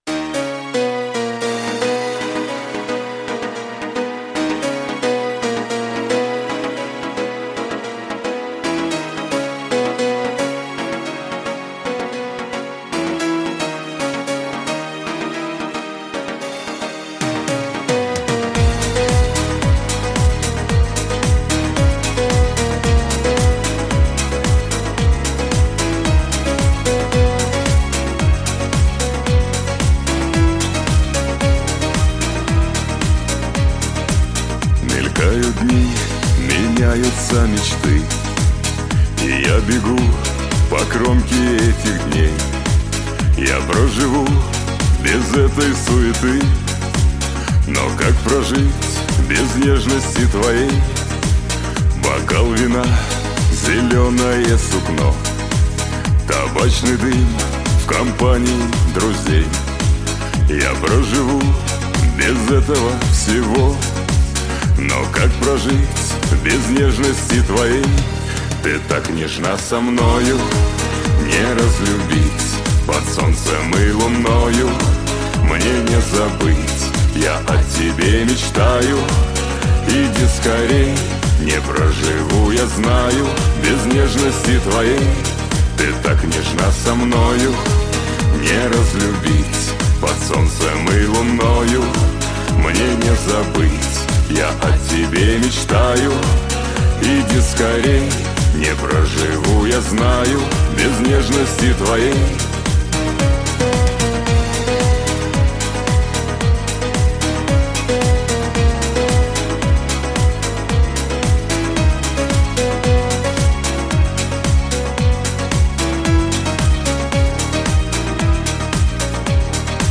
русский шансон для застолья